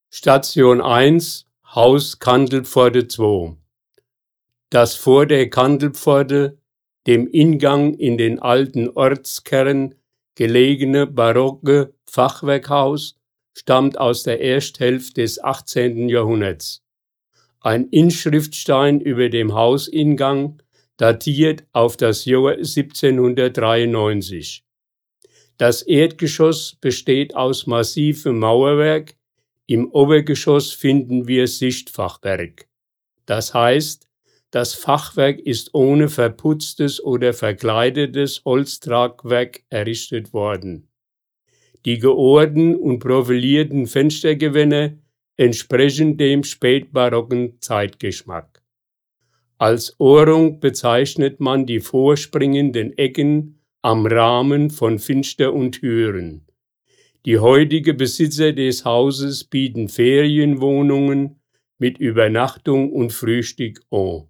Hier können Sie die Hörfassung der Stationsbeschreibung abspielen!